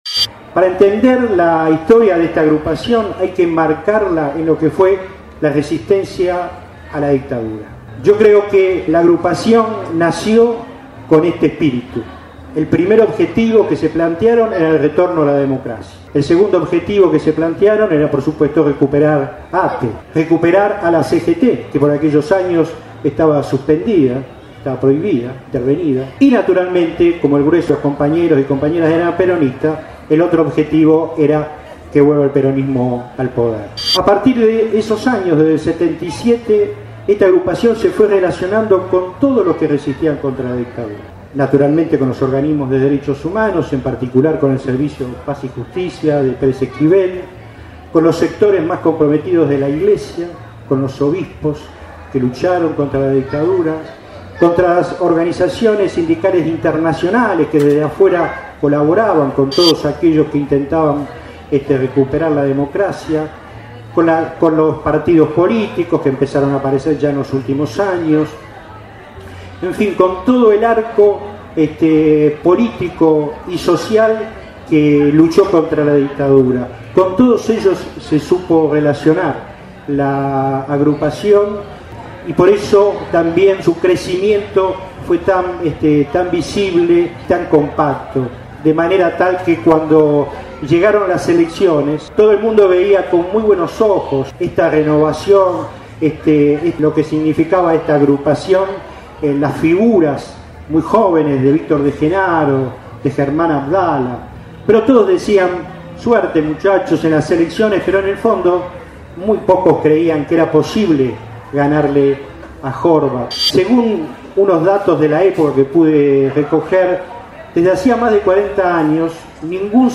LA HISTORIA DE ANUSATE | FERIA DEL LIBRO DEL MOVIMIENTO DE LXS TRABAJADORXS